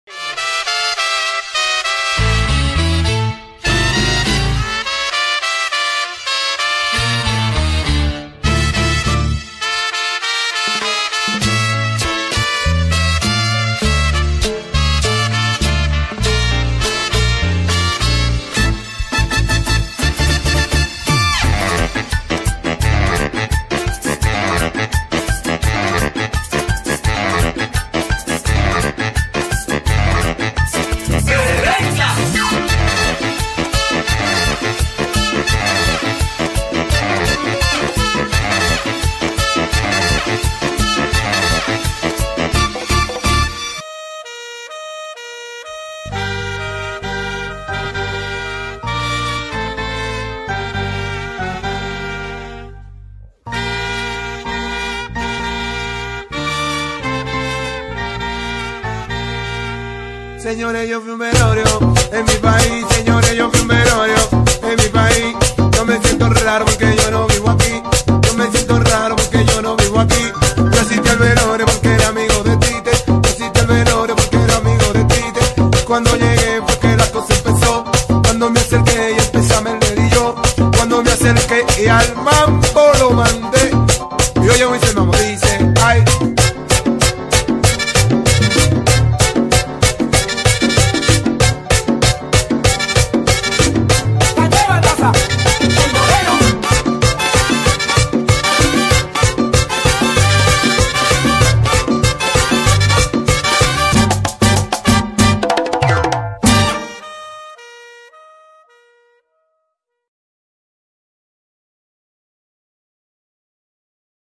Merengue Urbano